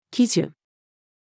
喜寿-female.mp3